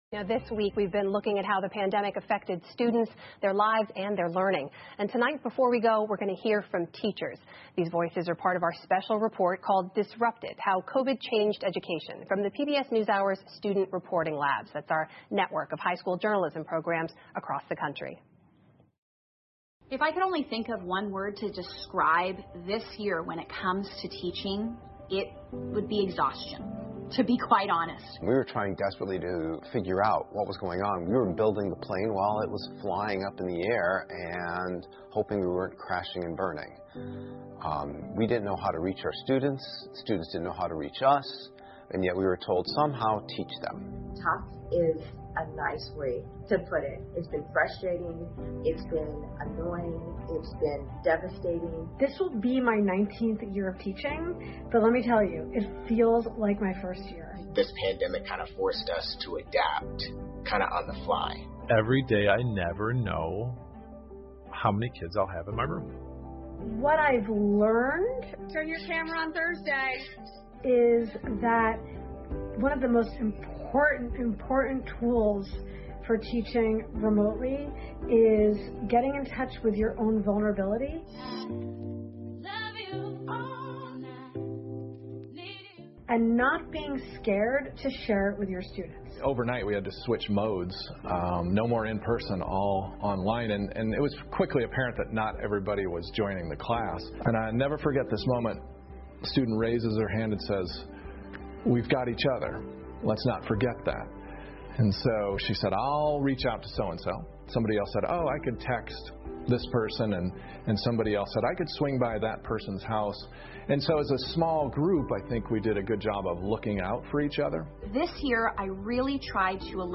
PBS高端访谈: 听力文件下载—在线英语听力室